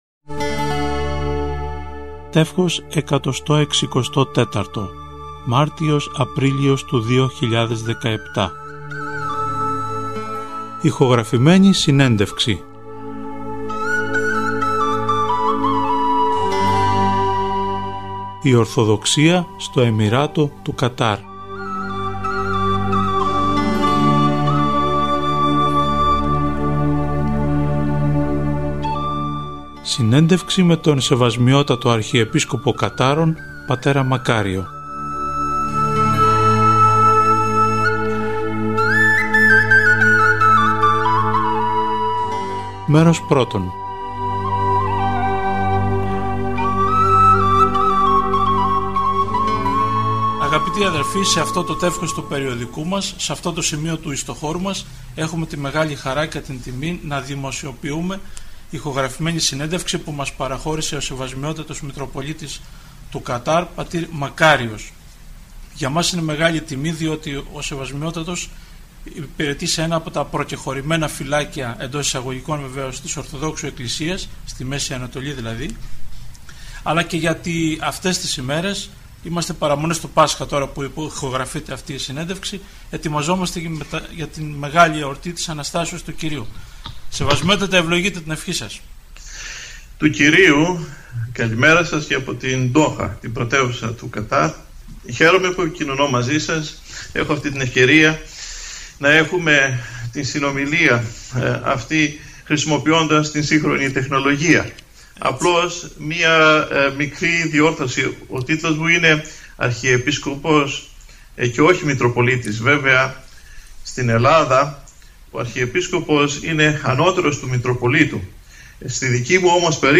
Πρόκειται για μια εντελώς συμπτωματική επιλογή ηχογραφημένων συνεντεύξεων, που παρεχώρησαν στο ηχητικό περιοδικό του συλλόγου μας, διάφορα εξέχοντα πρόσωπα της κοινωνίας μας.